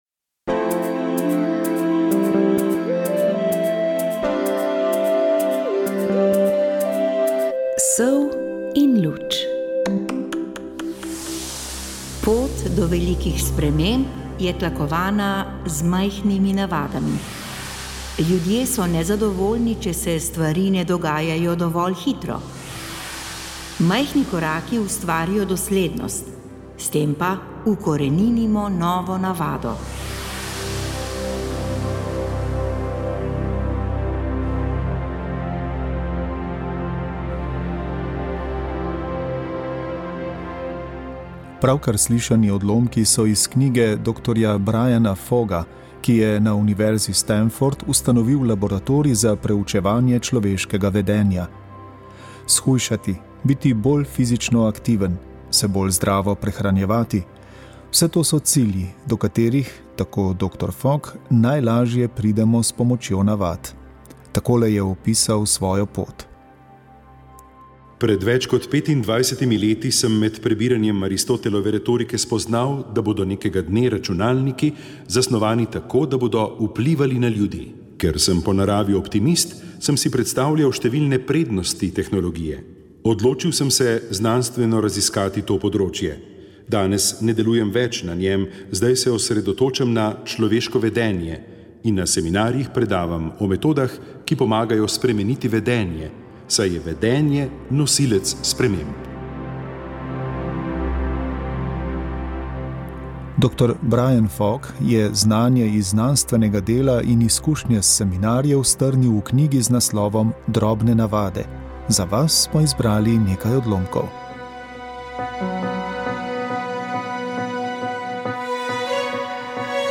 Pevska skupina Društva Šola zdravja Domžale
Zelo aktivni so tudi člani, oziroma članice omenjenega društva v Domžalah, ki jih 10-o leto poleg jutranje telovadbe, druži tudi ljudska pesem. Pevsko skupino društva Šola zdravja Domžale smo predstavili v oddaji o ljudski glasbi.